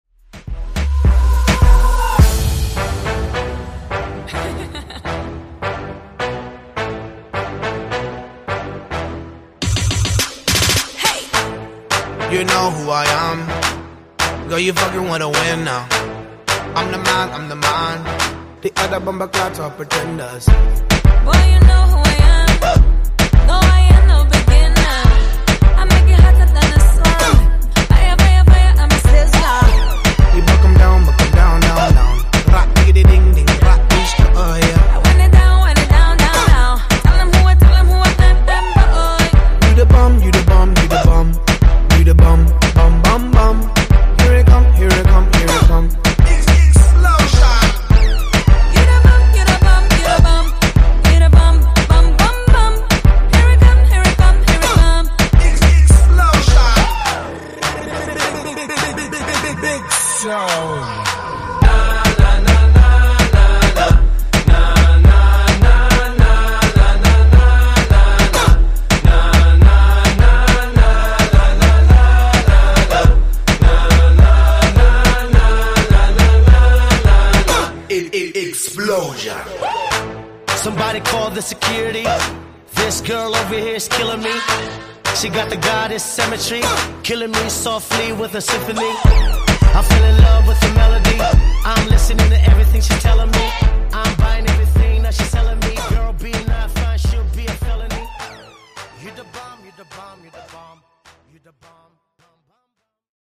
Genre: RE-DRUM
Dirty BPM: 105 Time